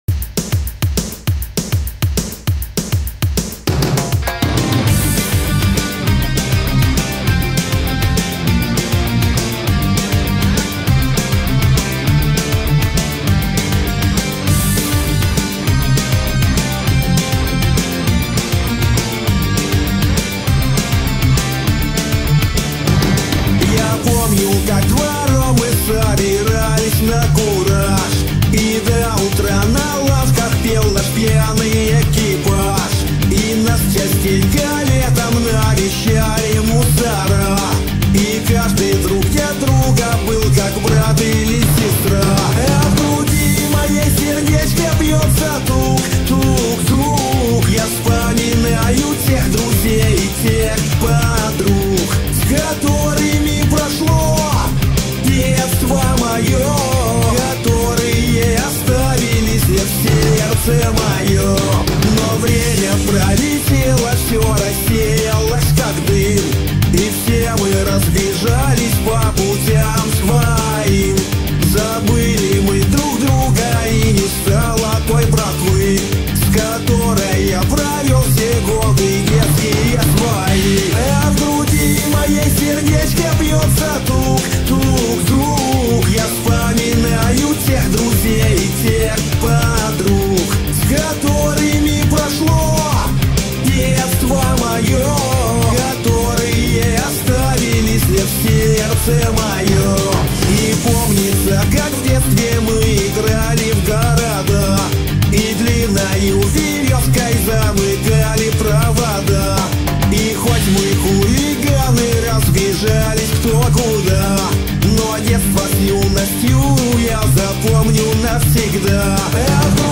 Зажигательных песен